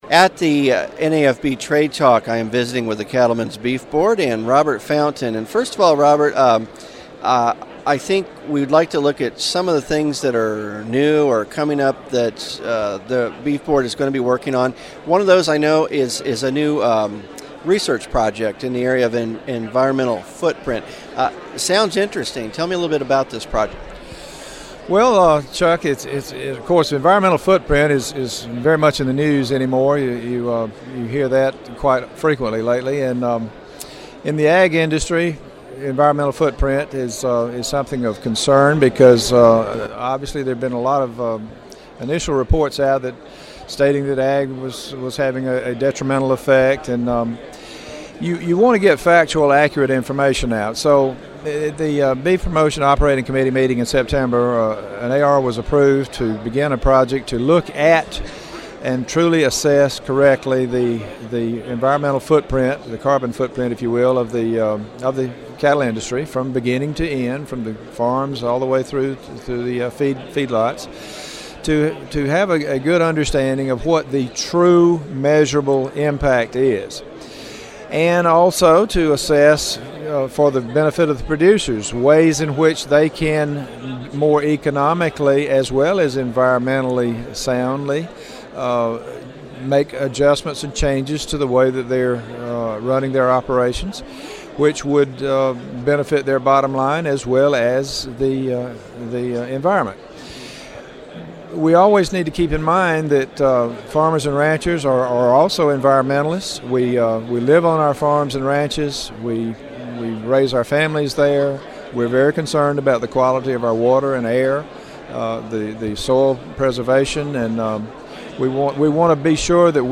CBB Interview